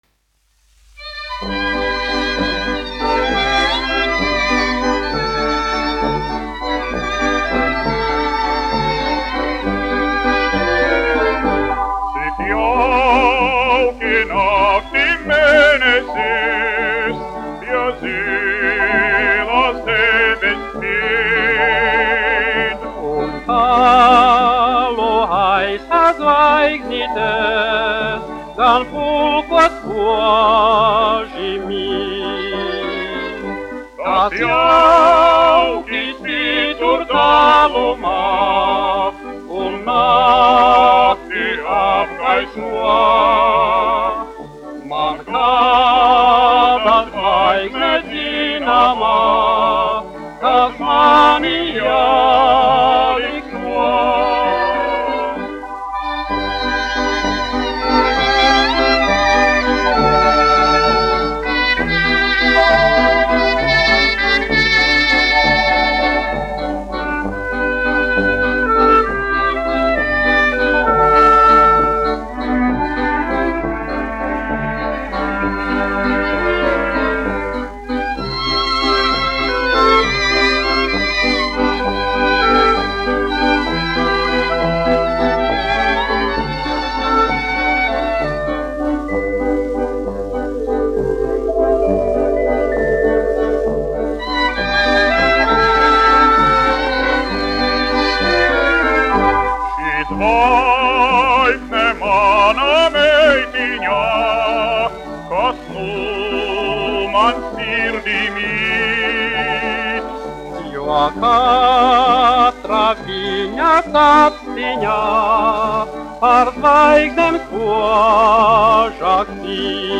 1 skpl. : analogs, 78 apgr/min, mono ; 25 cm
Populārā mūzika
Skaņuplate
Latvijas vēsturiskie šellaka skaņuplašu ieraksti (Kolekcija)